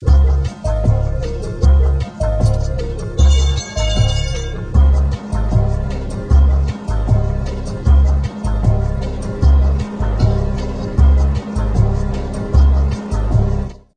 Mobile Klanginstallation zum Detmolder Blickwechsel die an die frühere Straßenbahn erinnern sollte
strassenbahn_Blickw_audio.m4a